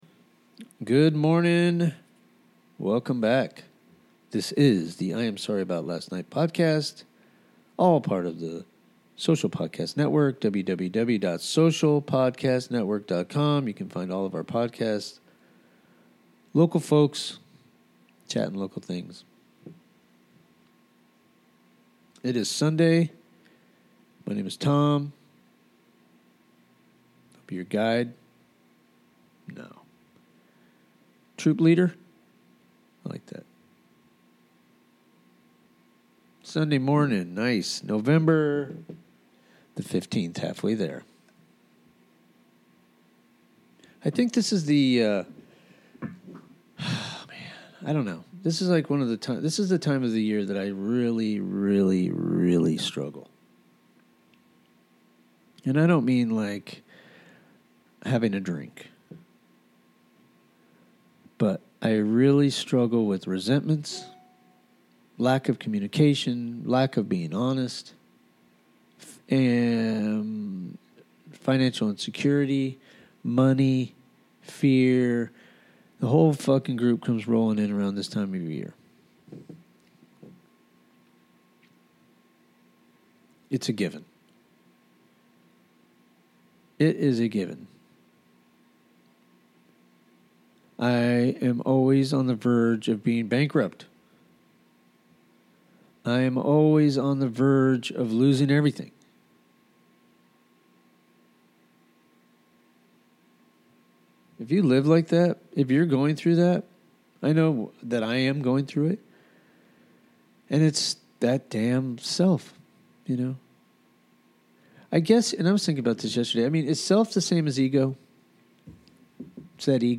Live- Good pain